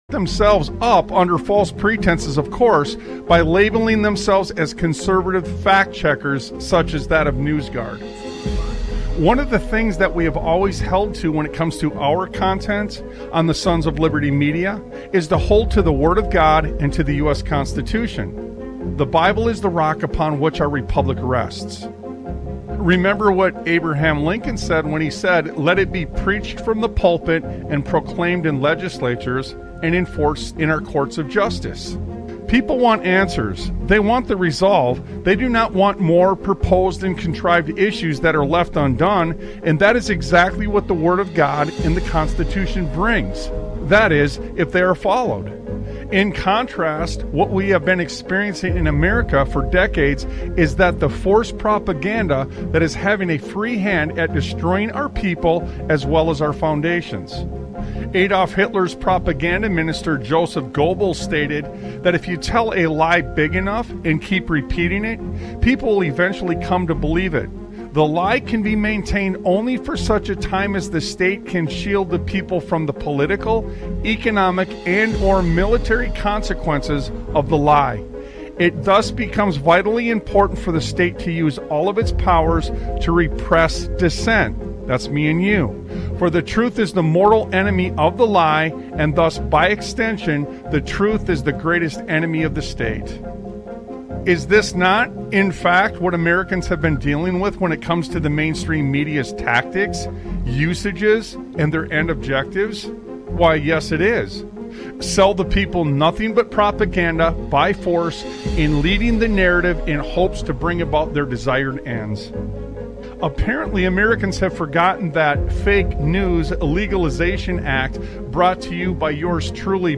Sons of Liberty Radio